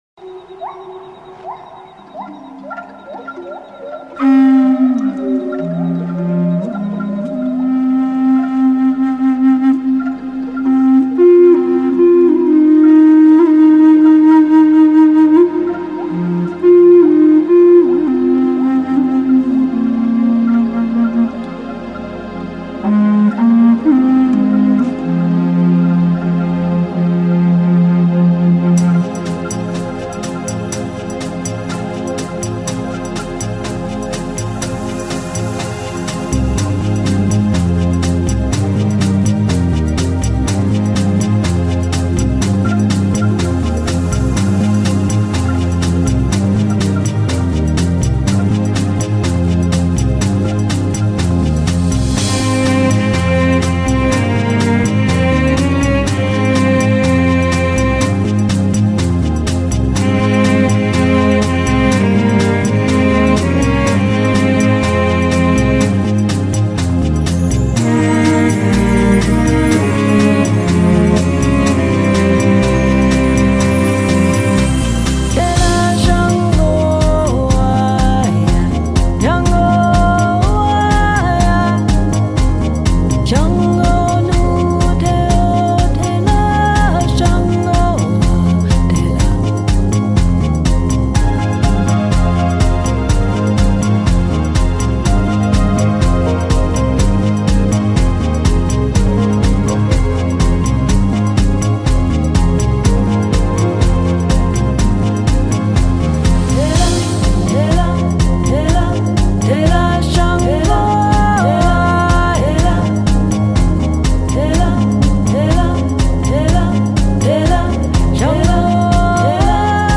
音乐类型：精神元素
整张专辑充满了印第安音乐风情。